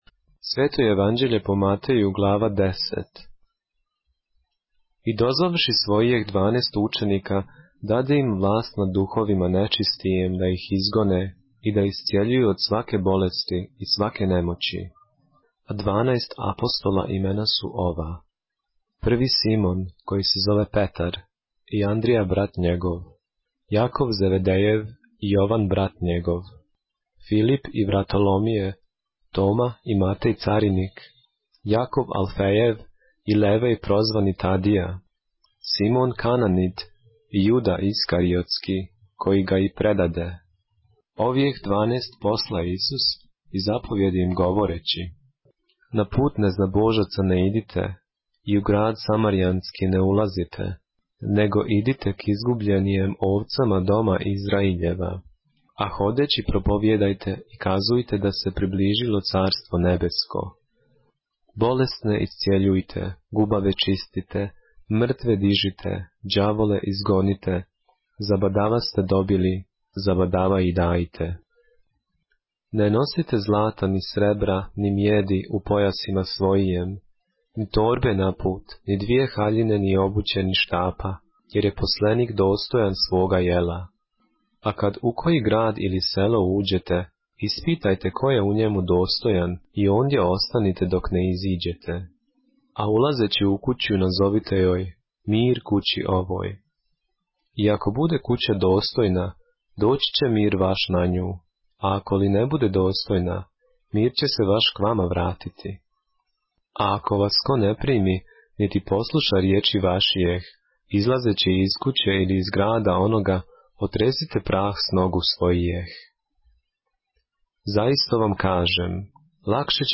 поглавље српске Библије - са аудио нарације - Matthew, chapter 10 of the Holy Bible in the Serbian language